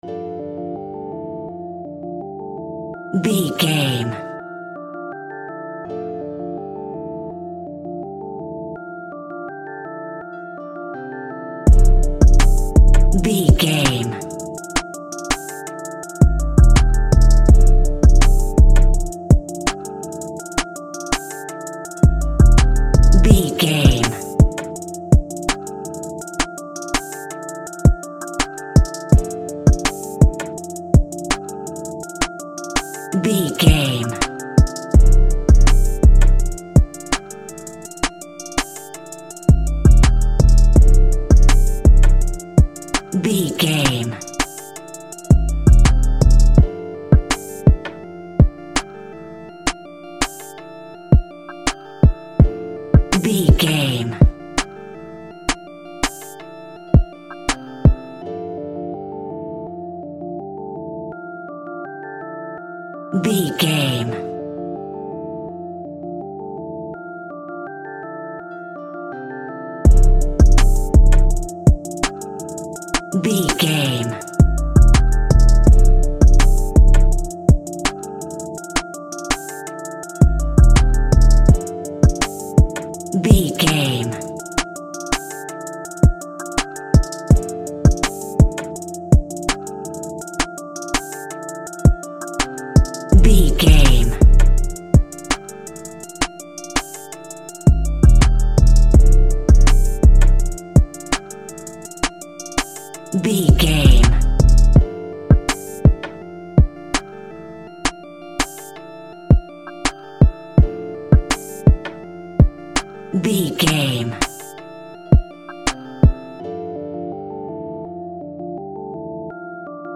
Ionian/Major
aggressive
driving
bouncy
energetic
dark
drums